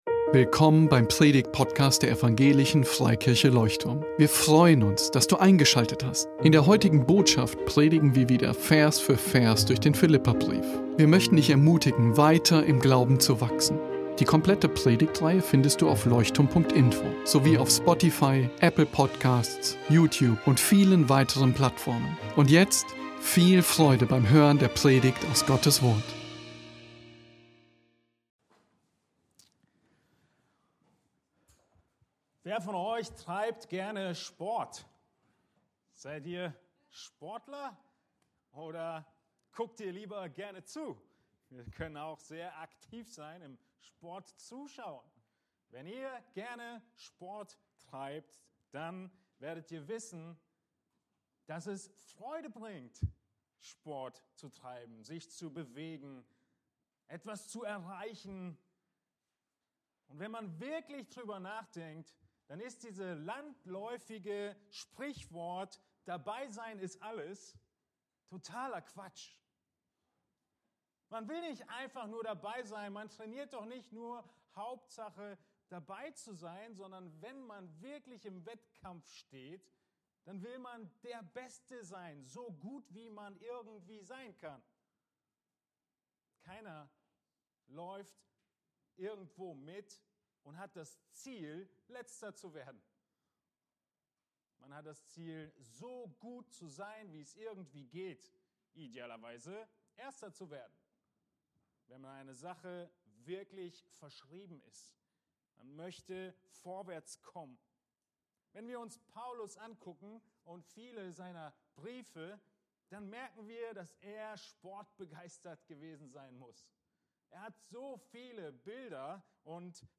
Predigtgliederung: Lagebestimmung: Du bist noch nicht am Ziel!